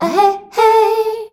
AHEHEY  F.wav